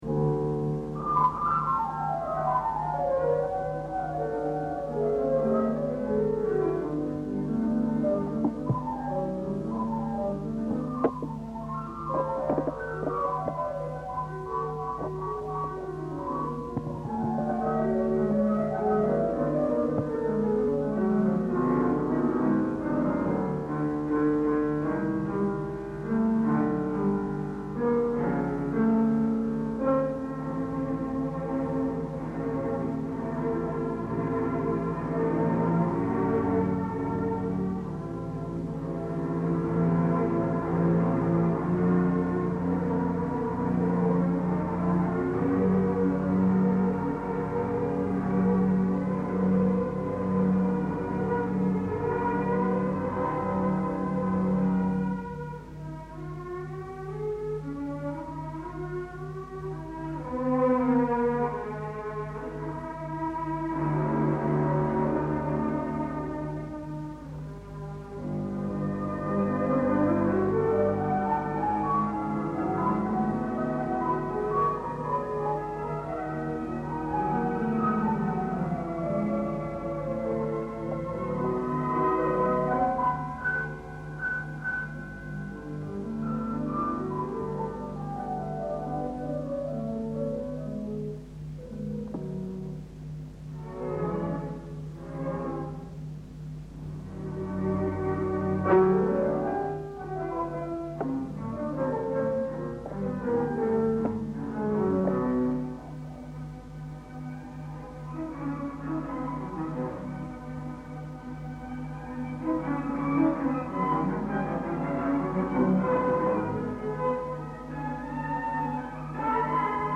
con l’esecuzione del primo concerto di D. Shostakovic op. 35 per pianoforte, tromba ed archi.